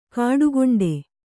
♪ kāḍu goṇḍe